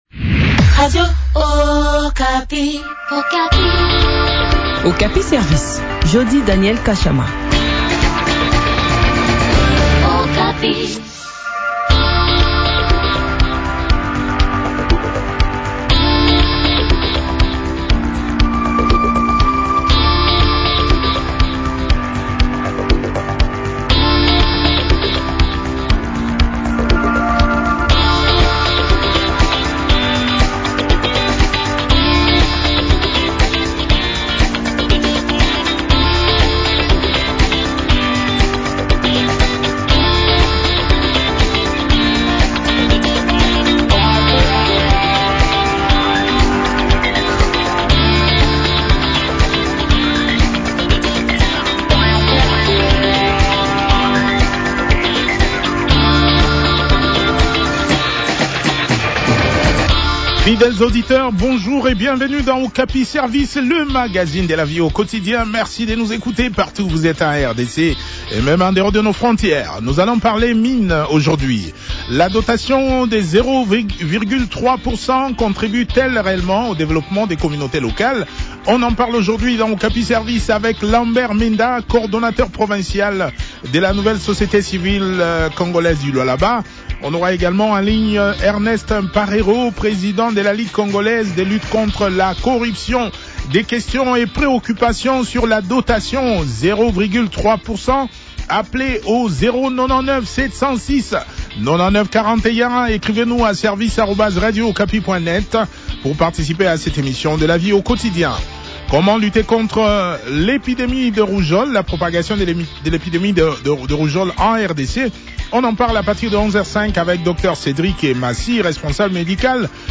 a également pris part à cette interview.